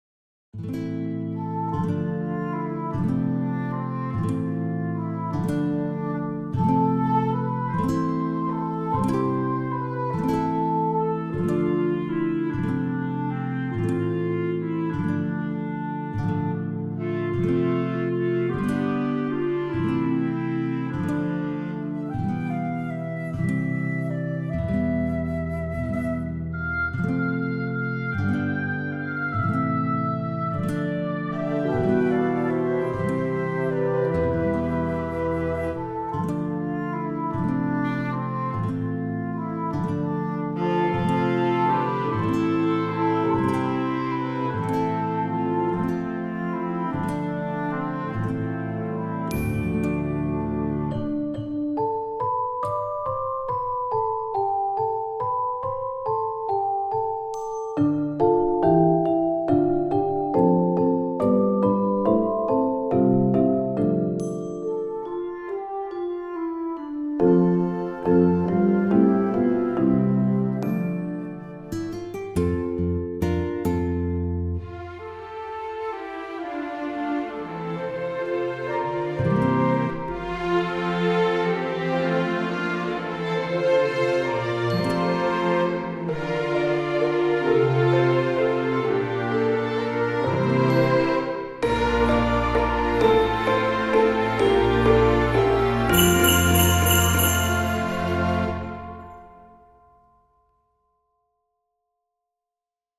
traditionals Dutch Christmas Medley V Orkest December 2024 PDF